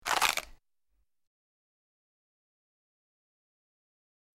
Crunch
Bite Into Bone Or Hard Vegetable, Single Crunches